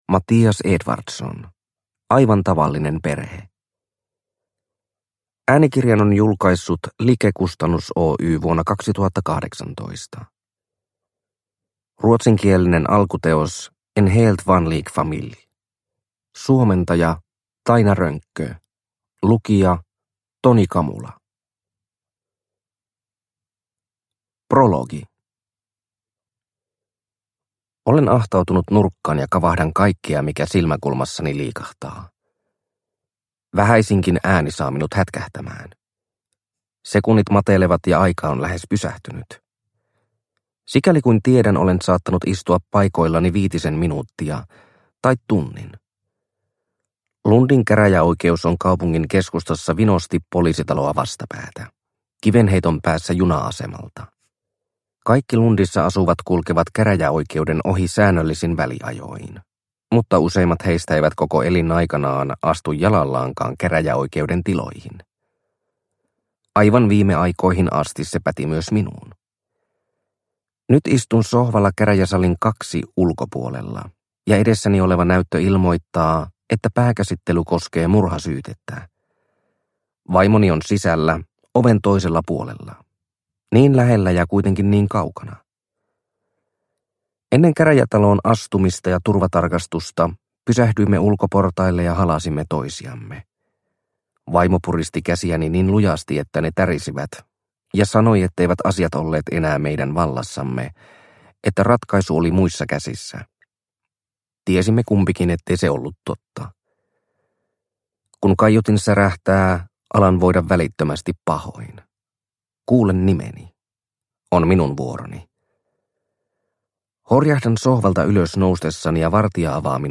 Aivan tavallinen perhe – Ljudbok – Laddas ner